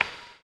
LO FI 5 OH.wav